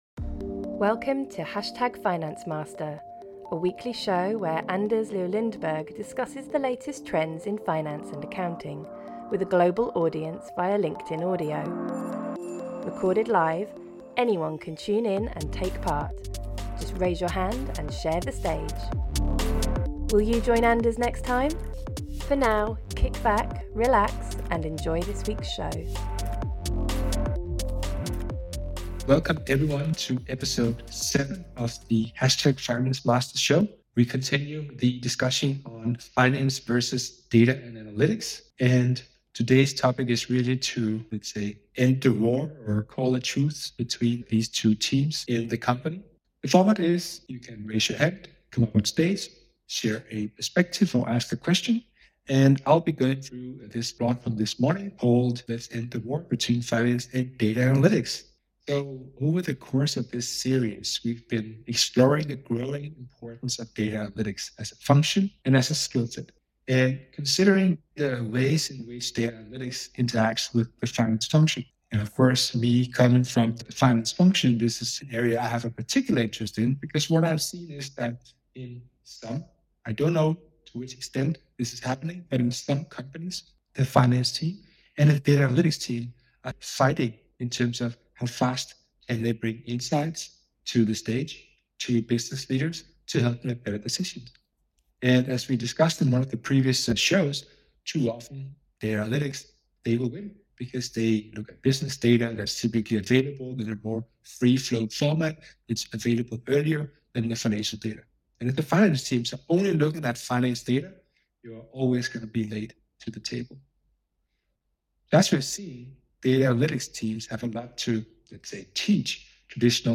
Please note: this episode encountered some audio issues during recording.